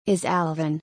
/-s/ + 母音 Is⎵Alvin[ɪz_ ælvɪn]「イズアルビン→イザァルビン」